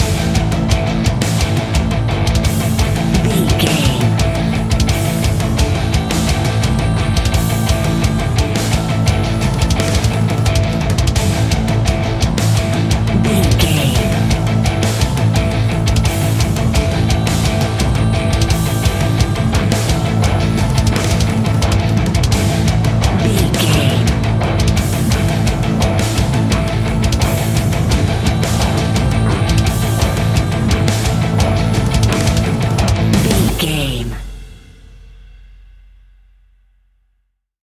Epic / Action
Fast paced
Aeolian/Minor
guitars
heavy metal
horror rock
Heavy Metal Guitars
Metal Drums
Heavy Bass Guitars